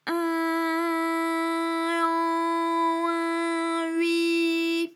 ALYS-DB-001-FRA - First, previously private, UTAU French vocal library of ALYS
in_in_an_in_ui.wav